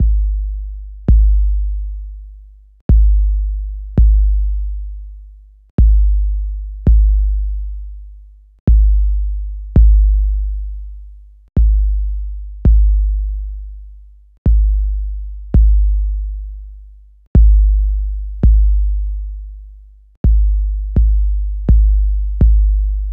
15-dt kick 2.wav